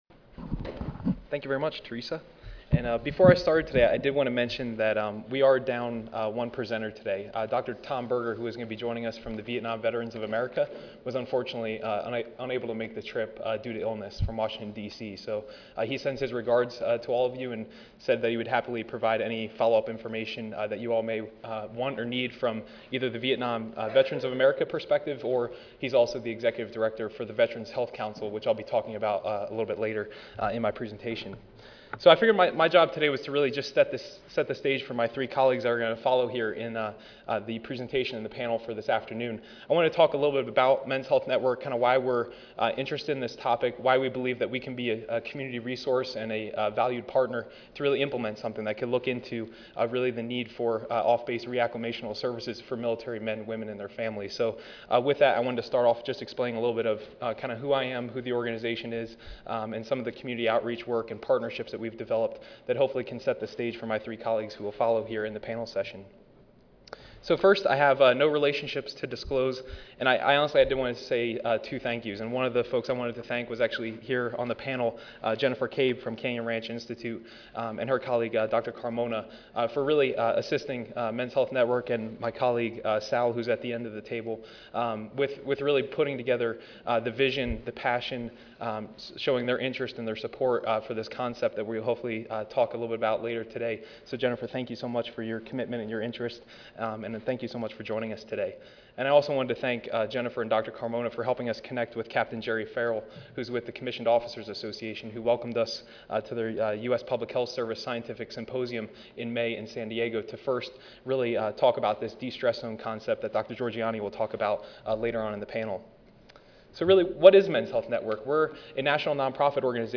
This panel discussion would discuss changes in families, children, and friends and how those changes, as well as their experience in the service, alter their own life afterward.